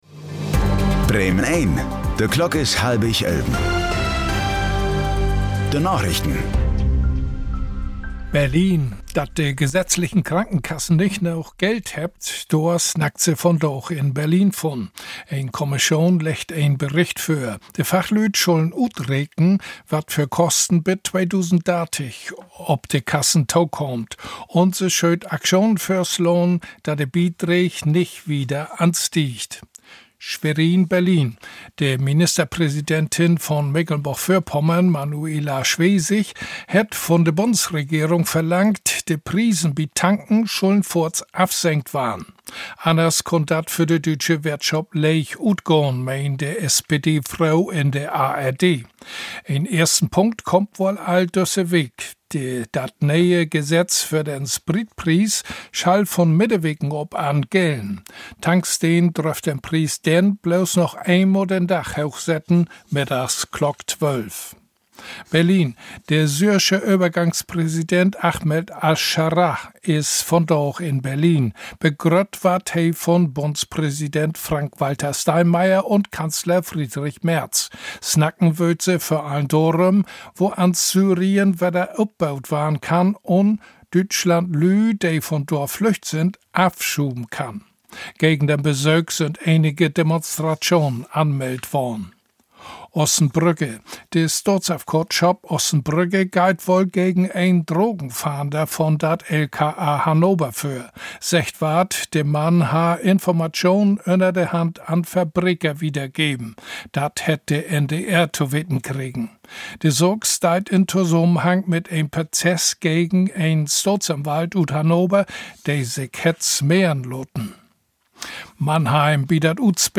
Plattdüütsche Narichten vun'n 30. März 2026